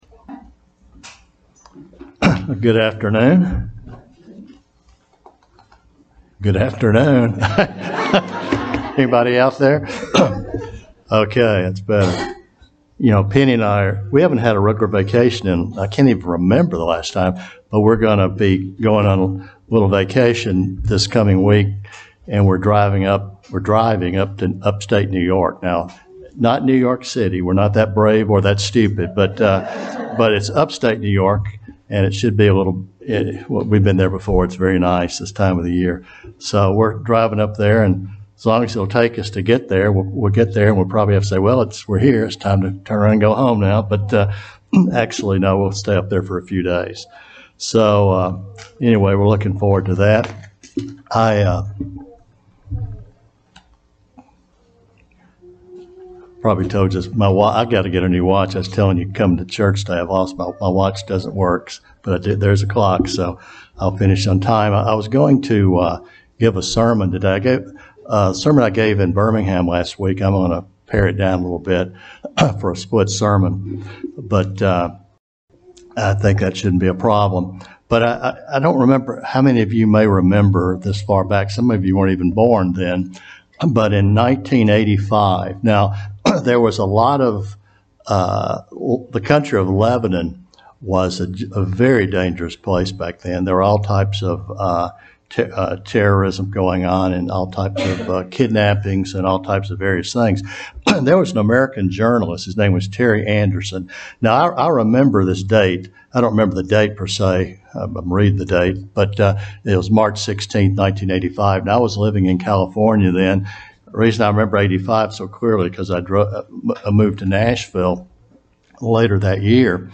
Sermons
Given in Huntsville, AL